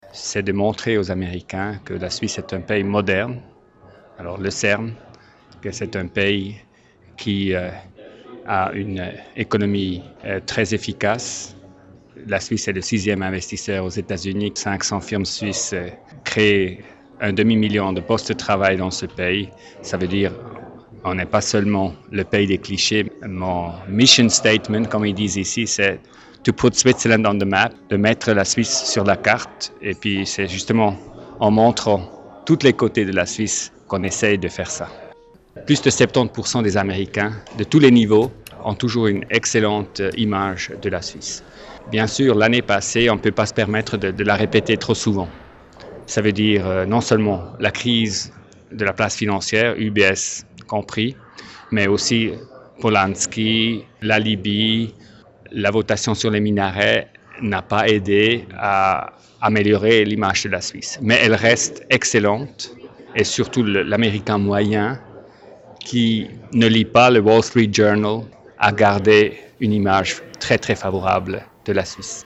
Urs Ziswiler, ambassadeur de Suisse aux Etats-Unis